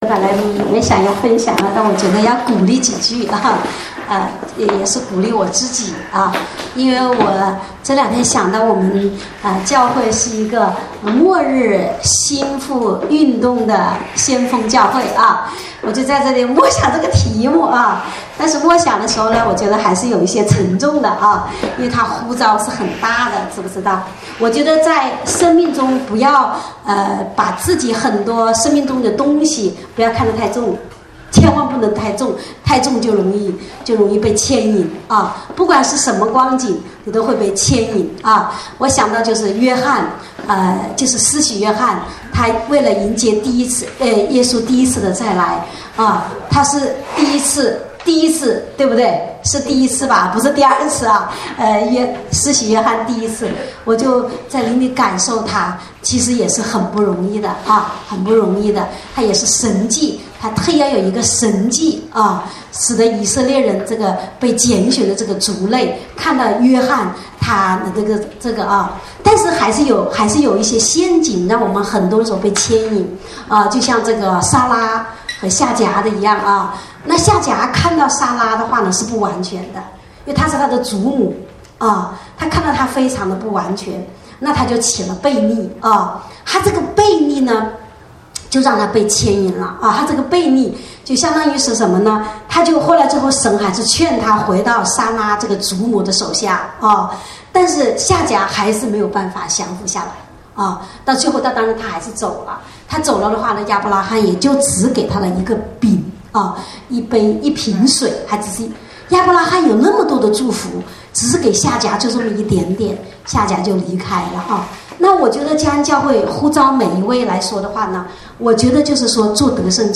正在播放：--主日恩膏聚会录音（2016-06-12）